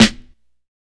007_Lo-Fi Agressive Snare.wav